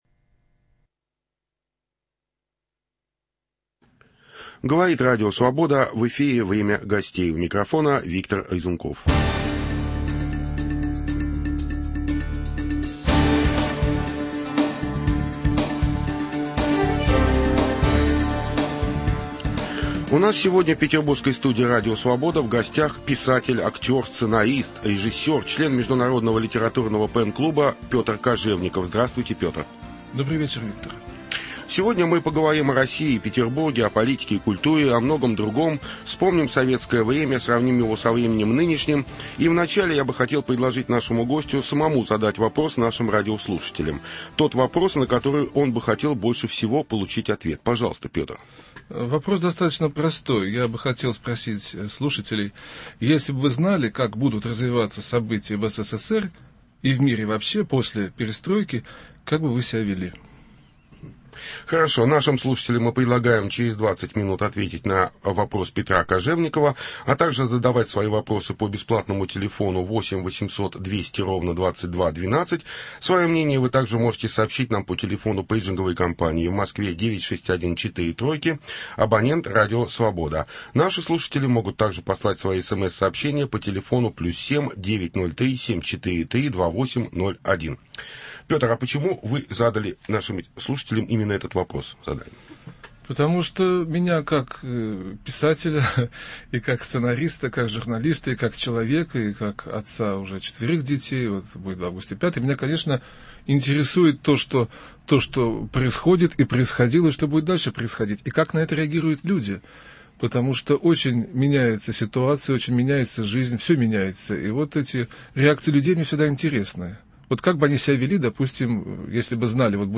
О прошлом, настоящем и будущем России и Петербурга беседуем с писателем